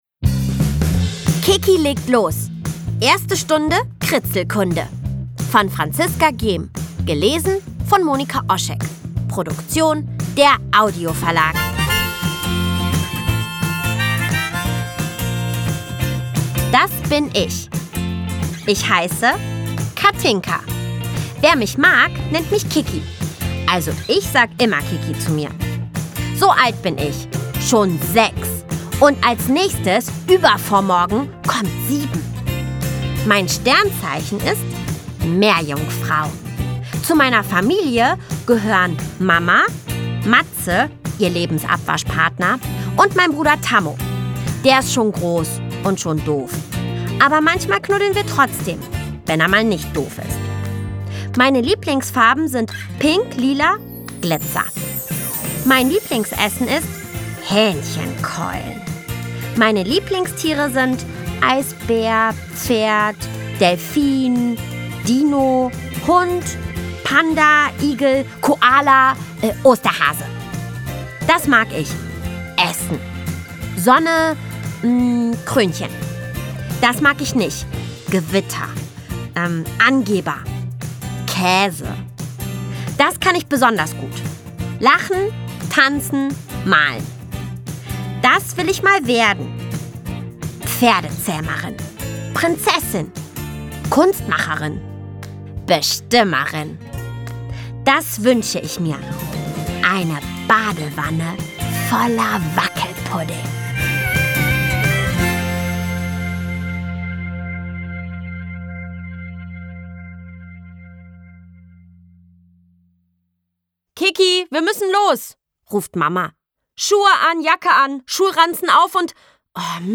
Ungekürzte szenische Lesung mit Musik